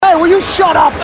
Category: Radio   Right: Personal
Tags: sports radio